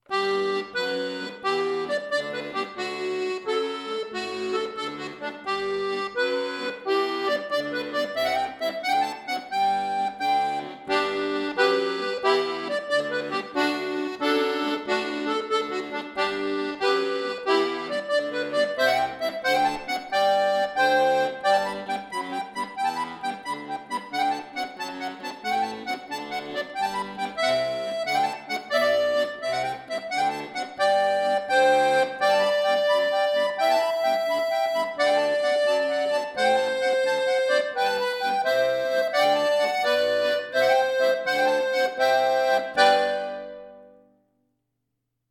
Folksong , Irish